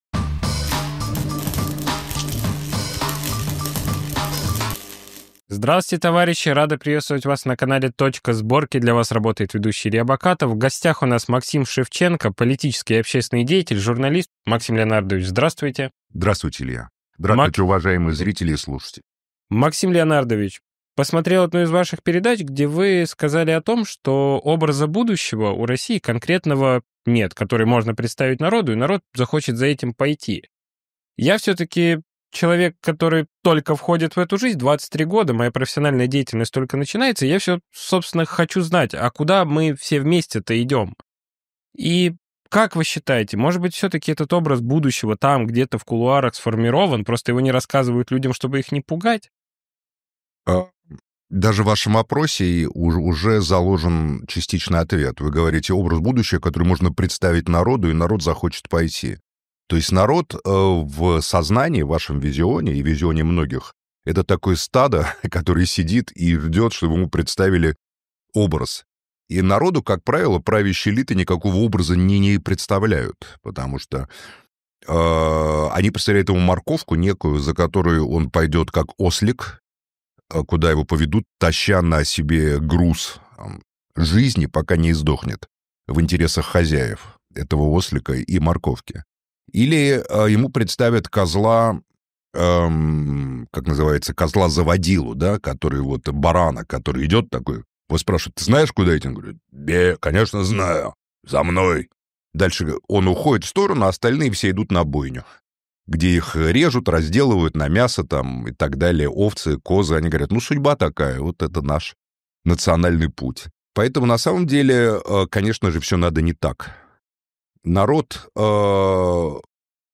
Журналист, общественный и политический деятель Максим Шевченко об отсутствии образа будущего России и причинах отсутствия интереса консолидации страны в пользу развития и созидания.